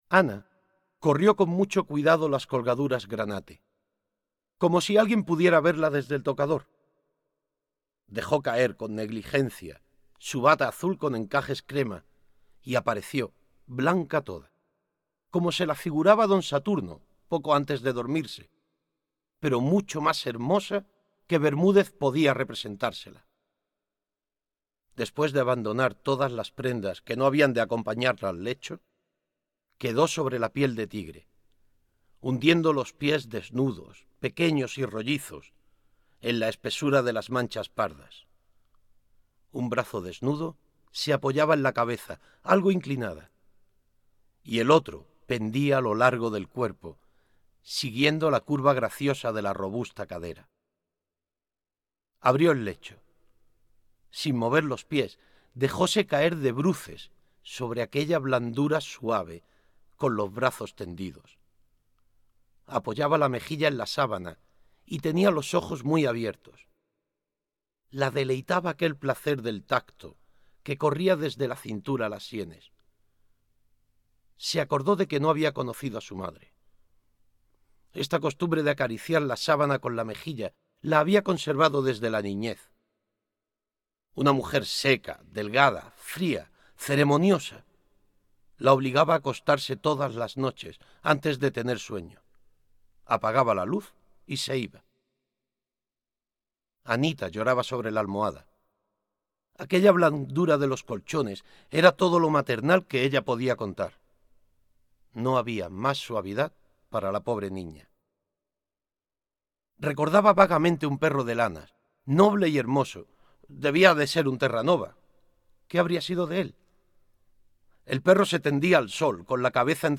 recitar